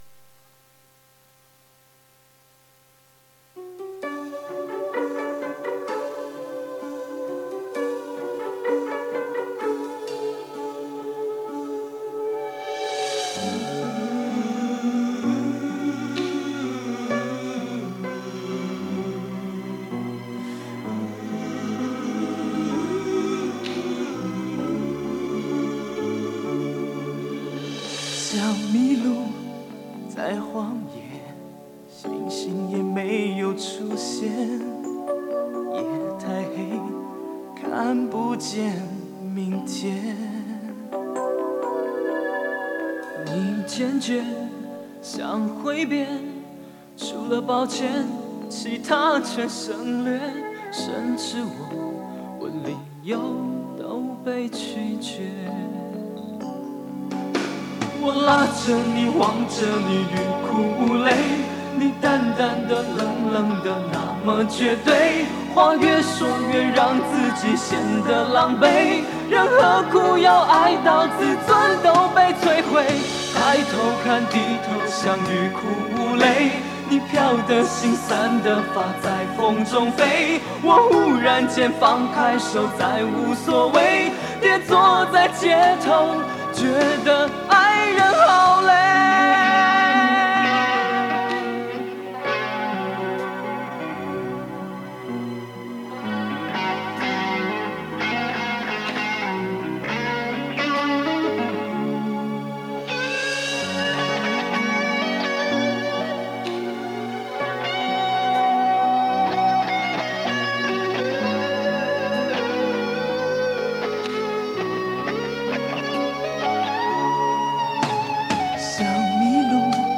磁带数字化：2022-07-31
唱的超好听 让人陶醉
声音清亮、纯净，音乐的表现是感性的，情绪是纤细、敏锐的，个性则沉稳、内敛。
声音低沉、厚实、而略带沙哑，音乐上擅长爵士、R&B、好动讨人欢喜，有爱搞笑。